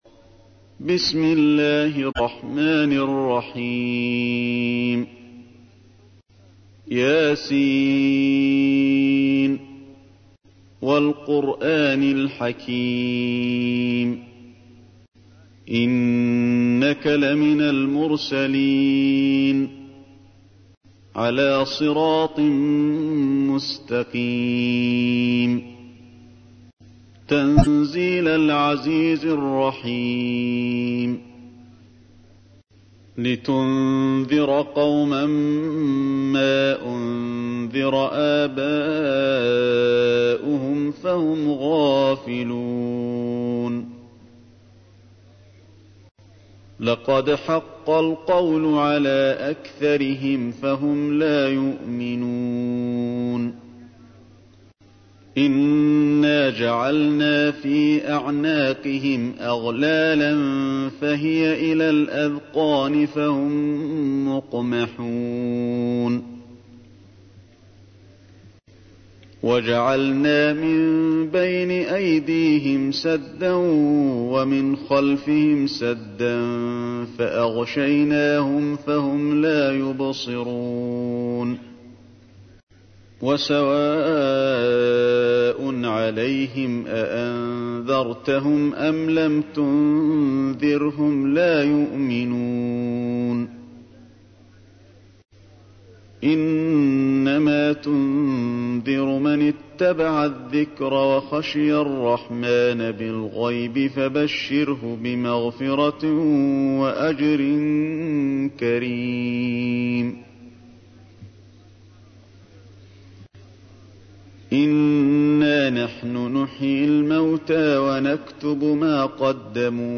تحميل : 36. سورة يس / القارئ علي الحذيفي / القرآن الكريم / موقع يا حسين